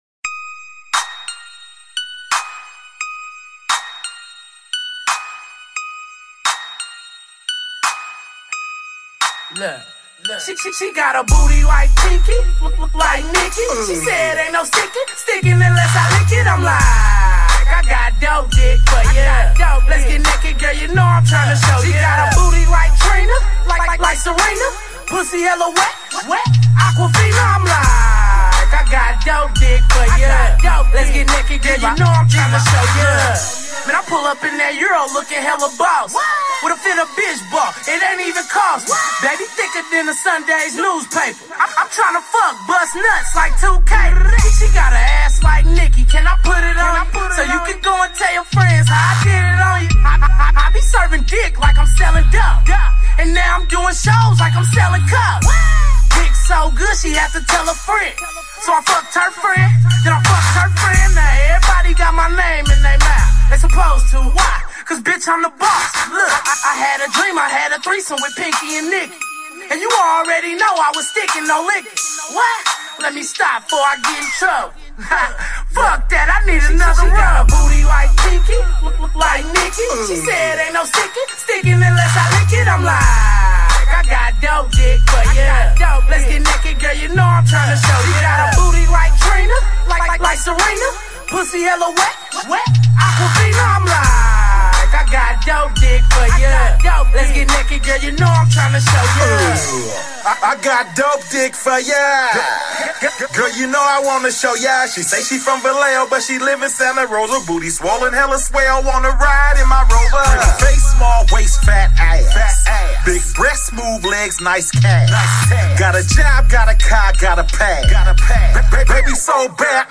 HIP HOP.